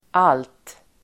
Uttal: [al:t]